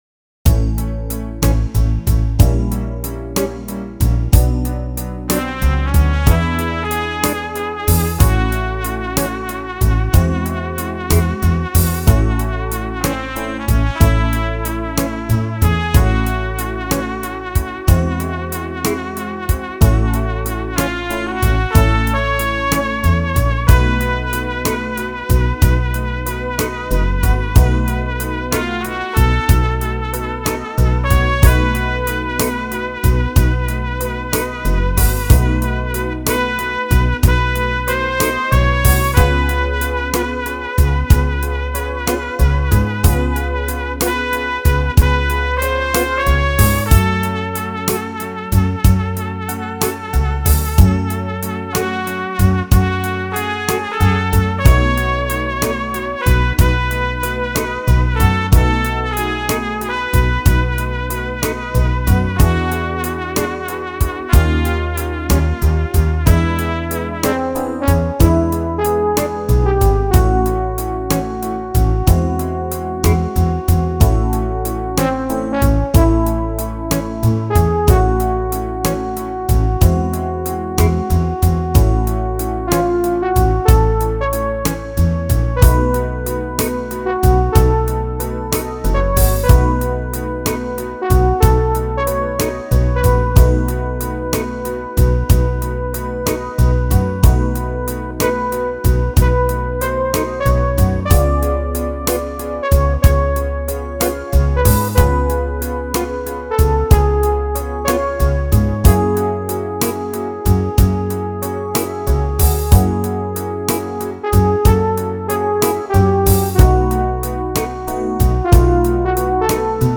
This one’s a classic ballad